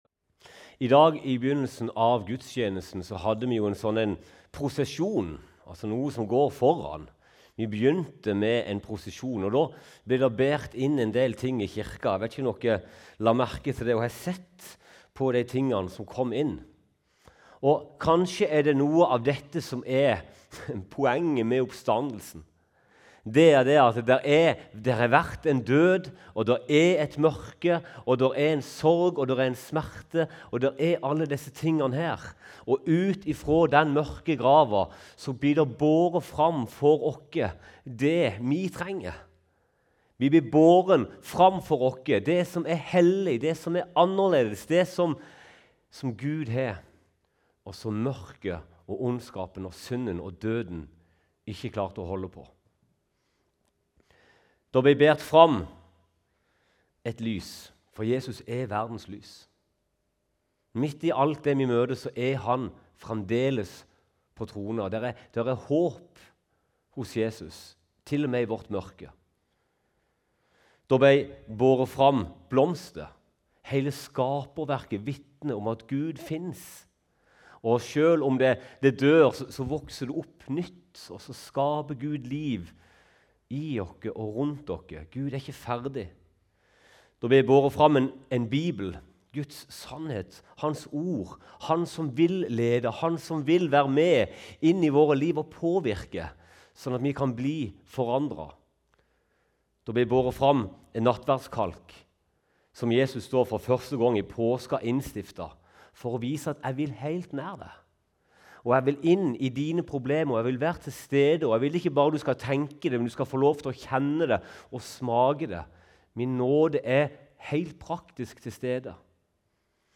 Siste taleopptak
paskedag25_lyd.mp3